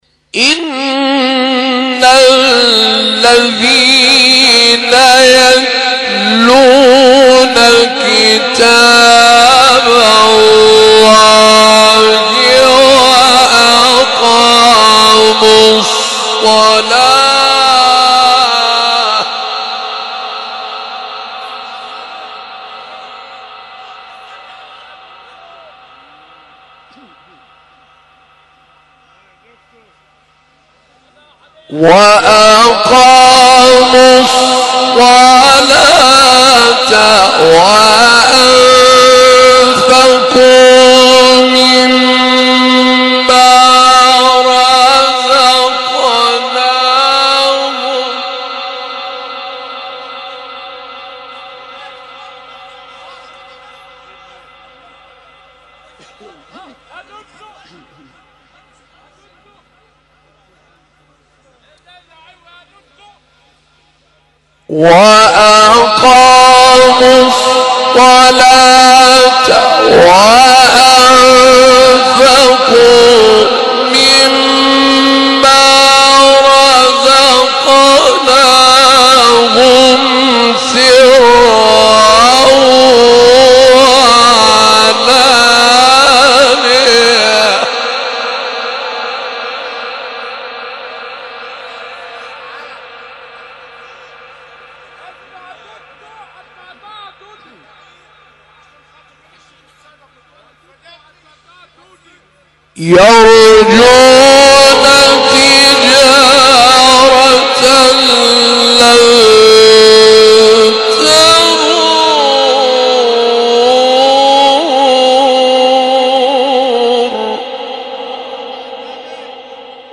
مقام صبا استاد عبدالفتاح طاروطی | نغمات قرآن | دانلود تلاوت قرآن